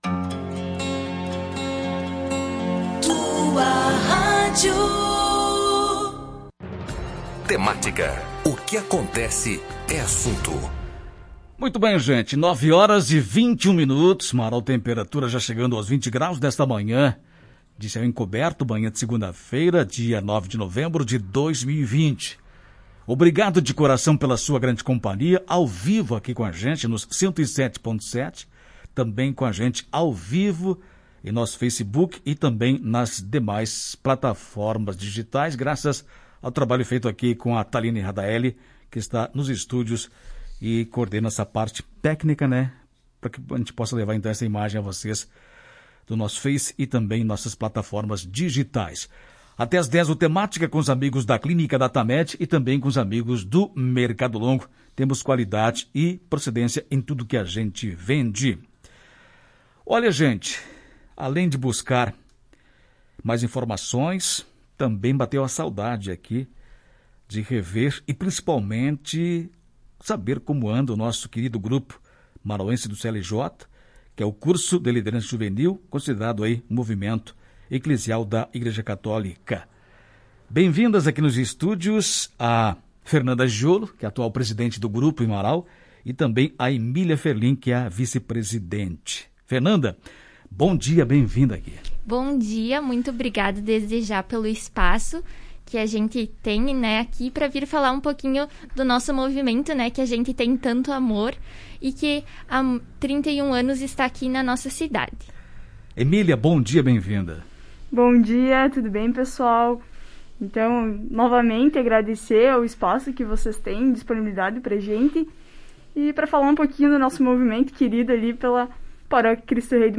A entrevista completa